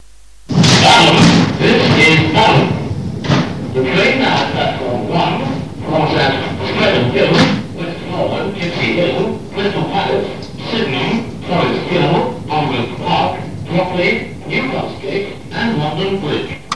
Balham pre-recorded platform announcement number 149 (year:1989)